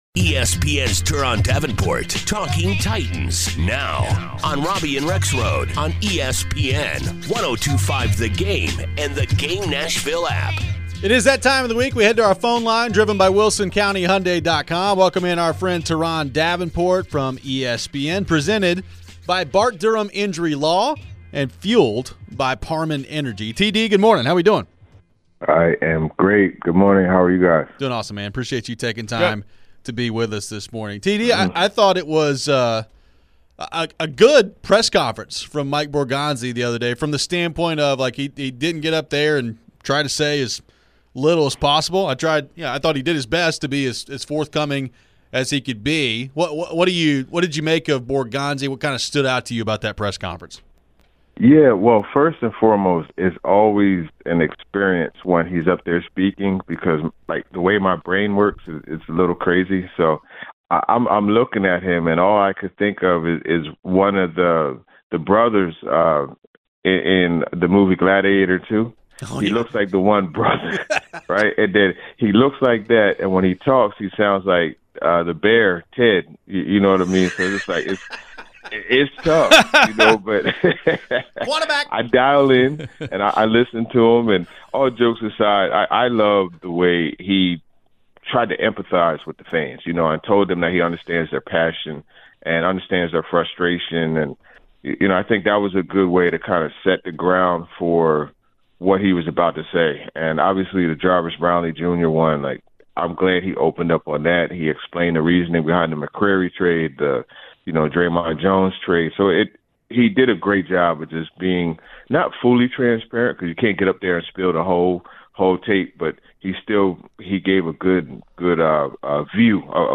We get into some of the latest on the coaching search. It's time to pick some games on Pigskin Pick 'Em. We close out the week with your final phones.